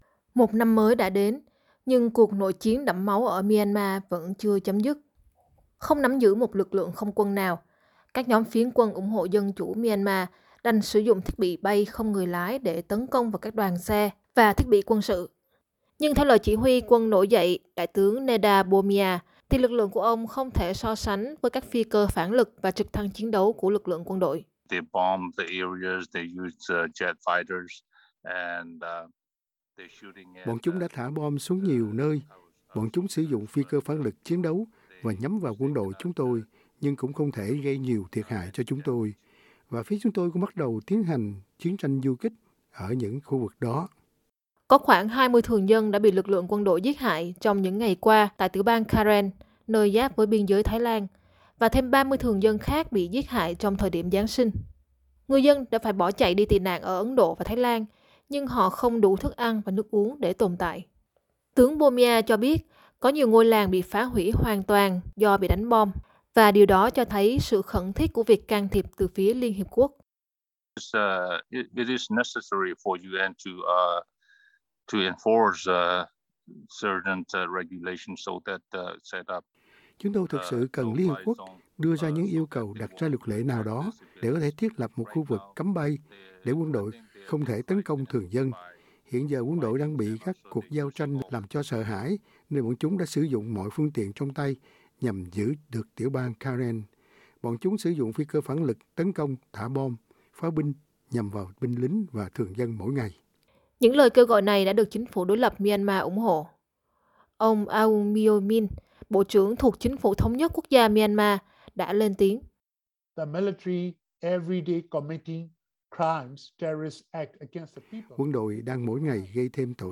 SBS có cuộc phỏng vấn độc quyền với một chỉ huy quân nổi dậy, người đang kêu gọi sự can thiệp từ quốc tế để bảo vệ thường dân.